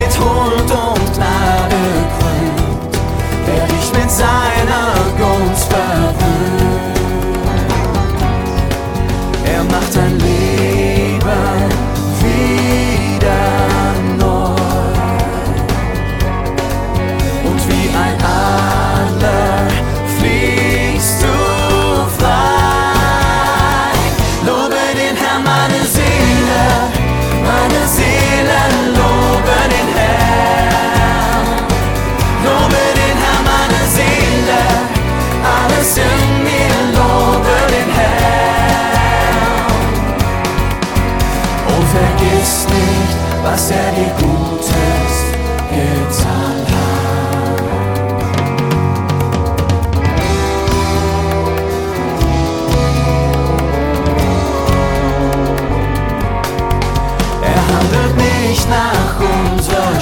Text) Worship 0,99 €